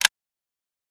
empty_shot.wav